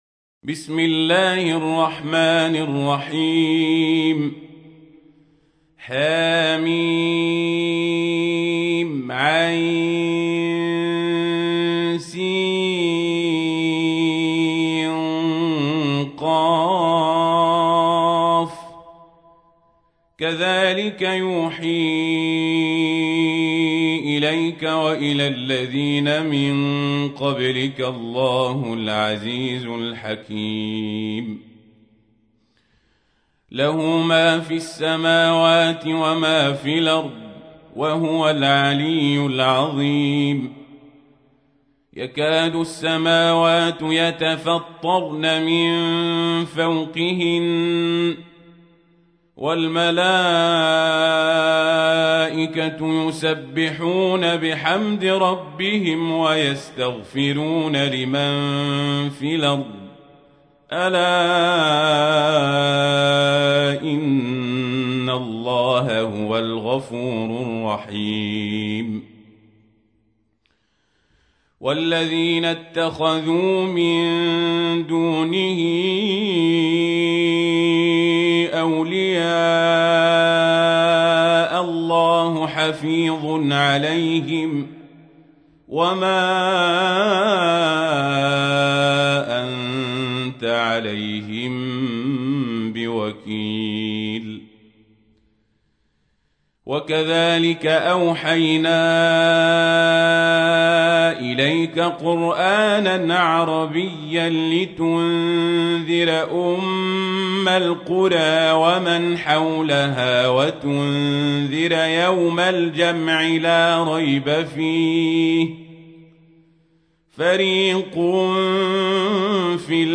تحميل : 42. سورة الشورى / القارئ القزابري / القرآن الكريم / موقع يا حسين